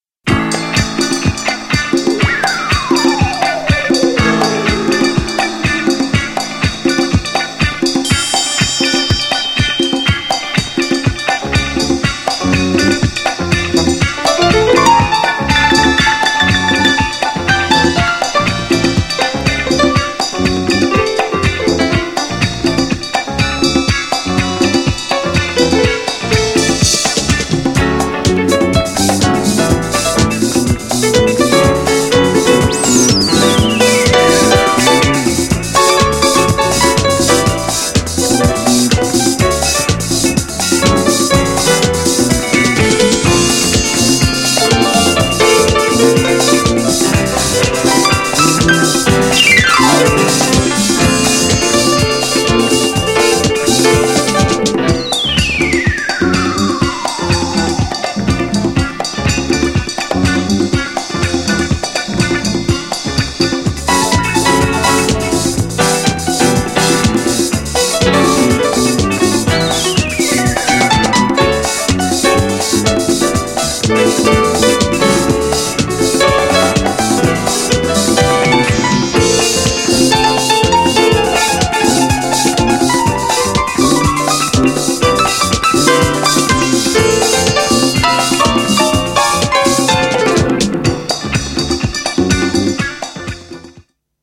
B面はピアノバージョンでこっちも最高!!
GENRE Dance Classic
BPM 121〜125BPM